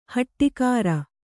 ♪ haṭṭi kāra